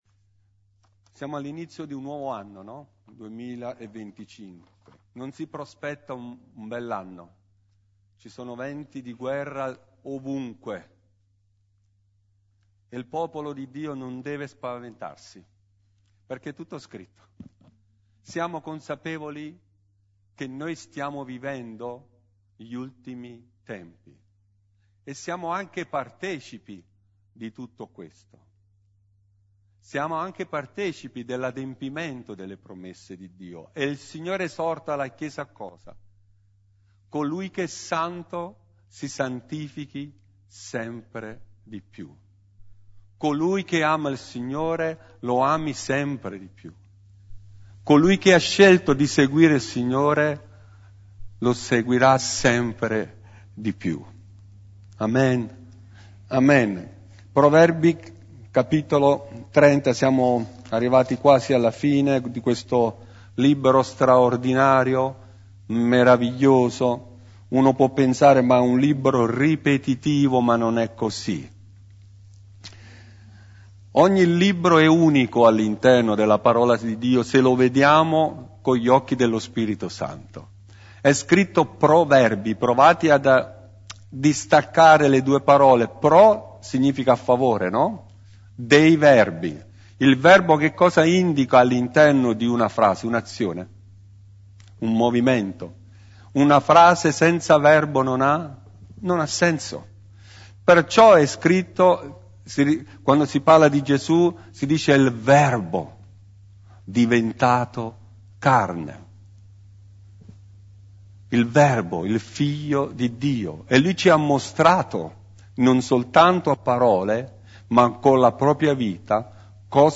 Studio biblico di mercoledì